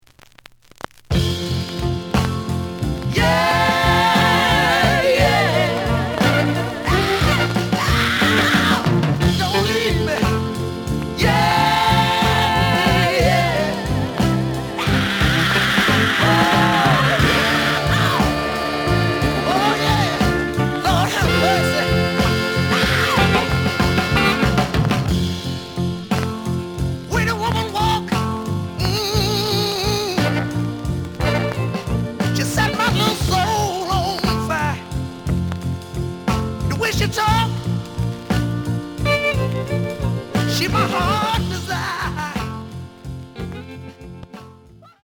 The audio sample is recorded from the actual item.
●Genre: Soul, 60's Soul
Some click noise on both sides due to scratches.)